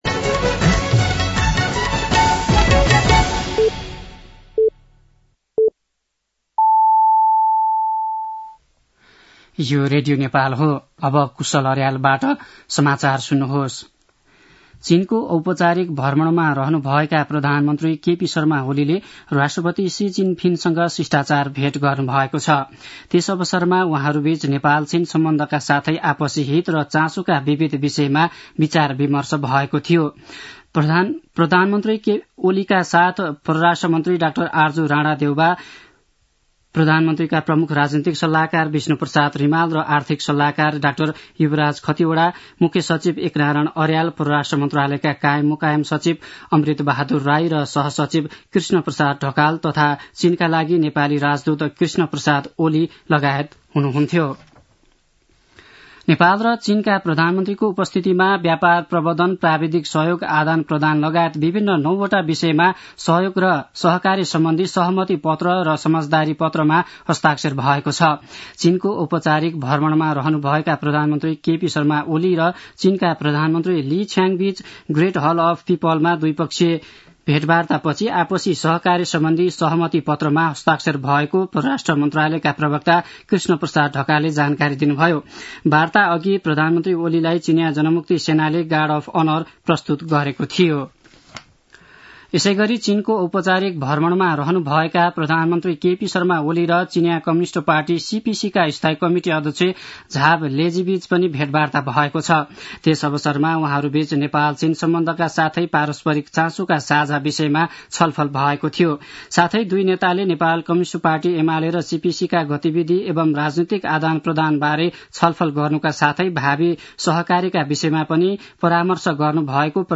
दिउँसो ४ बजेको नेपाली समाचार : १९ मंसिर , २०८१
4-pm-nepali-news-.mp3